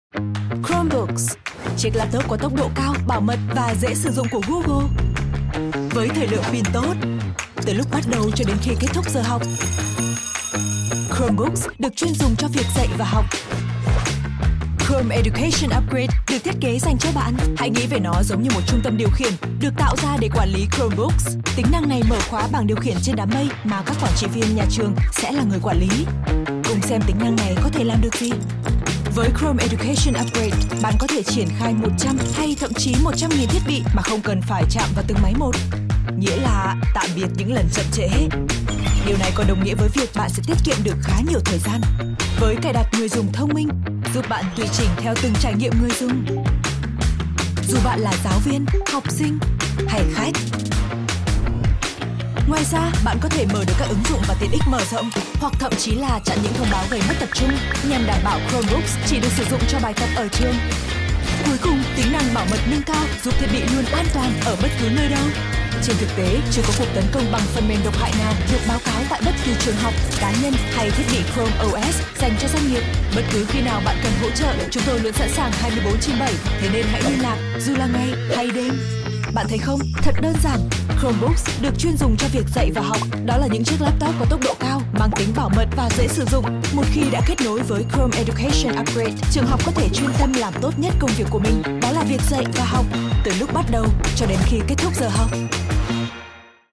Narration
I am a skilled, multi-lingual voice actor with over 13+ years of experience, native to Vietnam and speaking Vietnamese as my primary language (North Accent).
Records from my home studio or on location in Ho Chi Minh City and other connected cities throughout Vietnam.